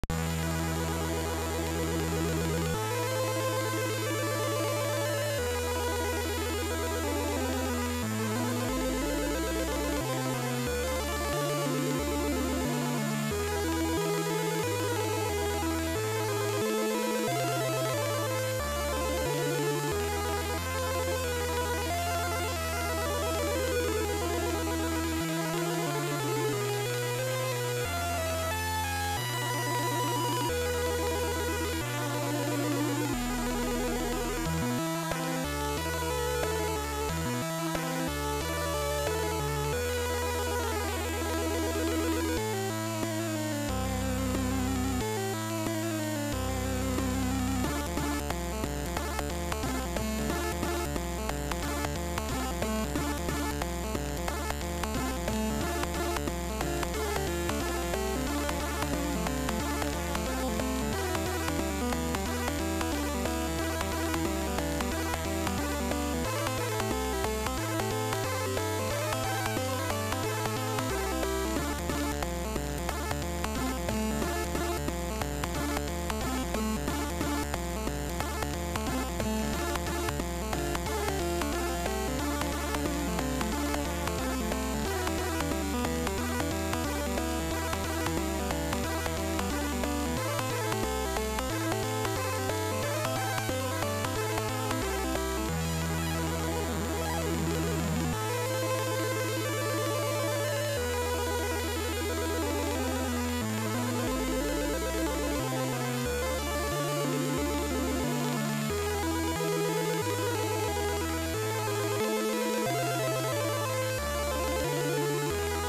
- eve1 gra pół tonu wyżej od eve.
+ oczywiście połowę ciszej